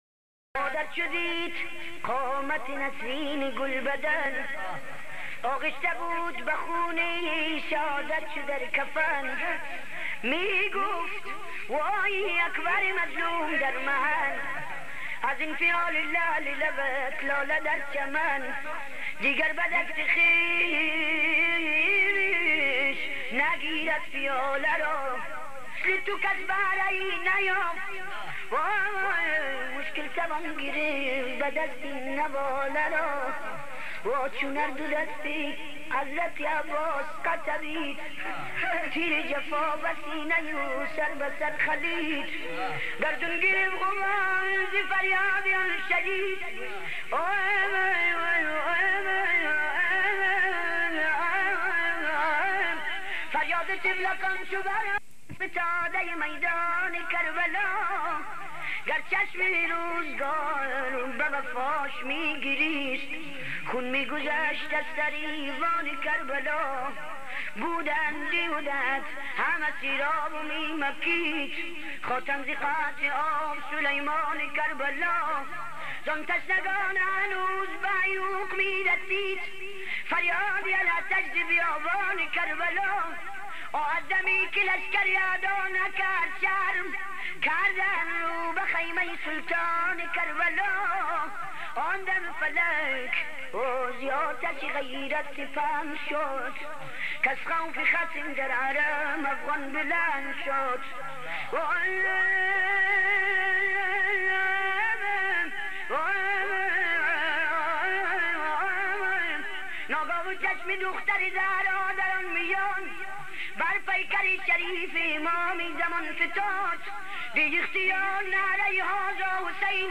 ترکیب بند محتشم با نوای سوزناک نعت خوان اهل سنت در 40 سال پیش
صدای دلنشین وملکوتی وحنجره ای توانا وخستگی ناپذیر
نعت خوانى
با نوای دل نشین و سوزناک خود آن را خوانده است